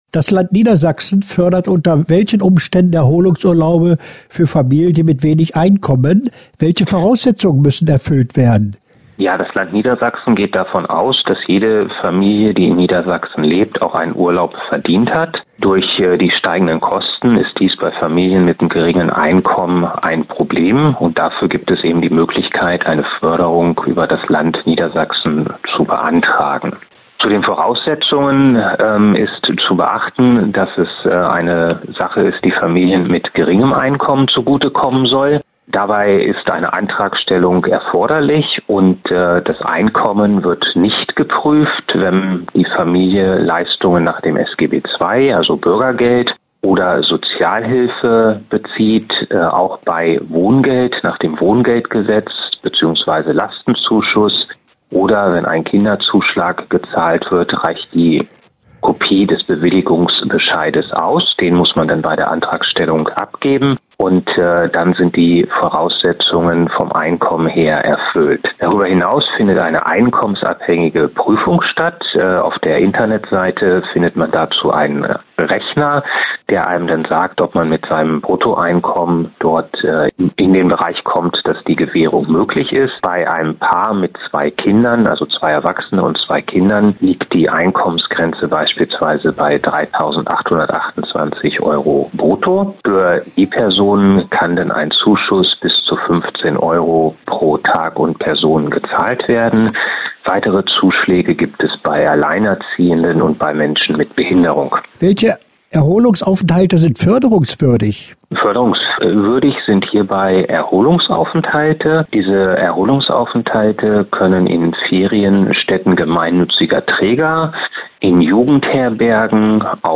Interview-Erholungsurlaub.mp3